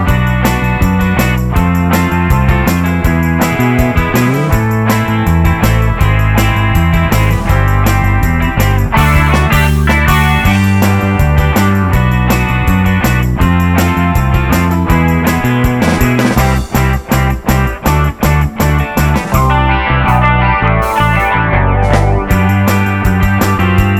With Intro Pop (1960s) 3:27 Buy £1.50